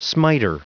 Prononciation du mot : smiter
smiter.wav